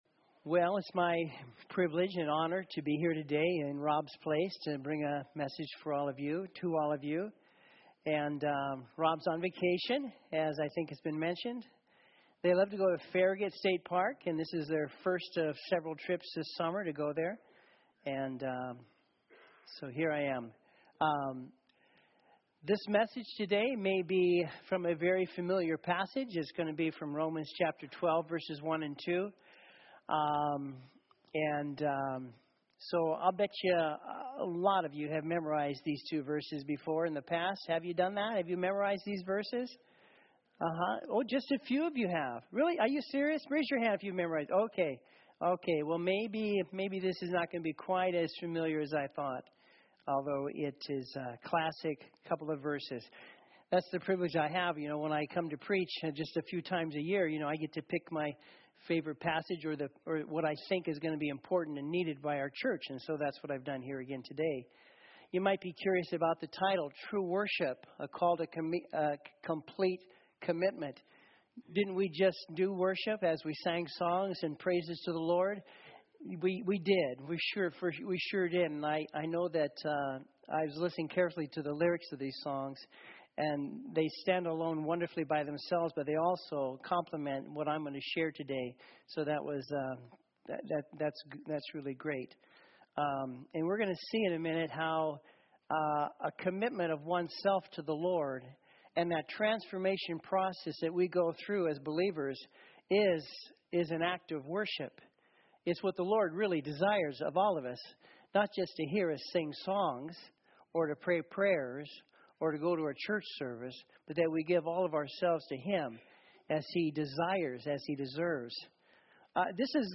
SERMONS True Worship